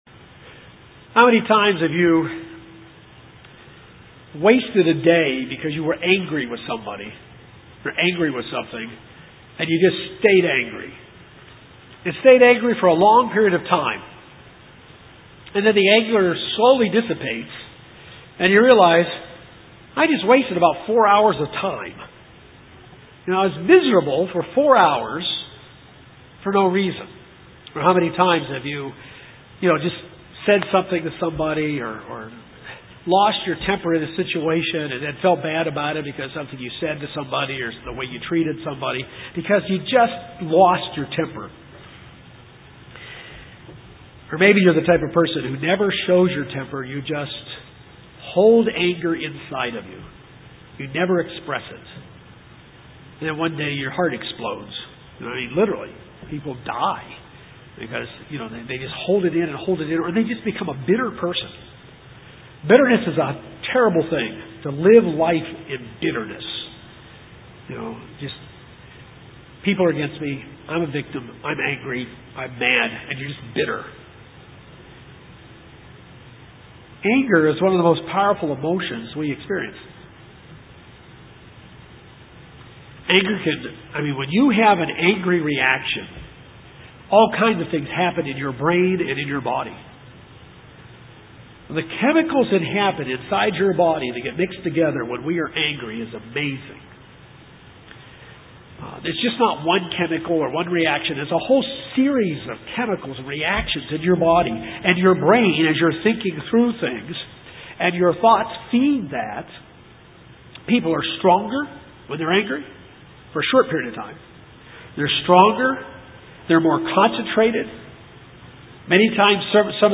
Uncontrolled anger can ruin our lives and stunt our spiritual growth. This sermon tells us how to deal with anger and properly handle it.